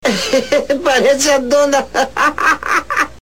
Seu Madruga está com o chapéu da Bruxa do 71 e Chaves começa a rir: parece a dona hahaha!